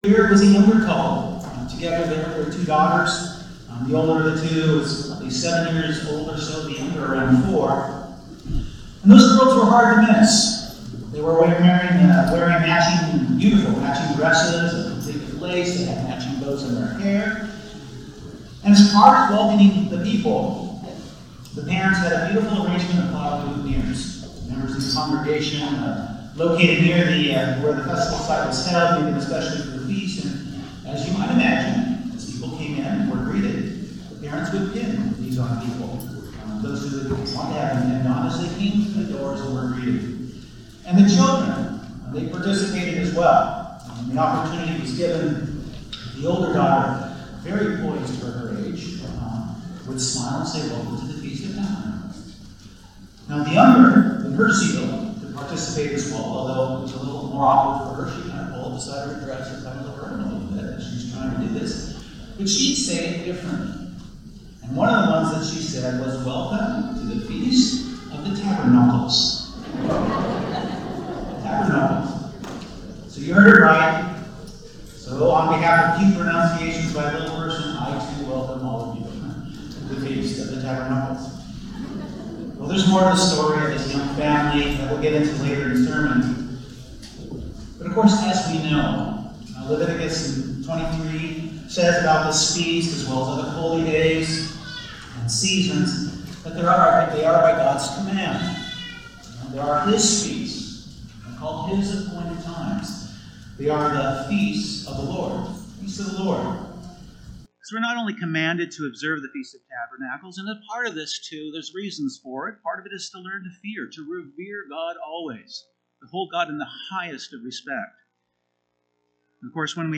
NOTE: The first two minutes of the audio is not clear, but the rest can be heard quite well. A split sermon given on the first day of the Feast of Tabernacles in Glacier Country, Montana.
This sermon was given at the Glacier Country, Montana 2020 Feast site.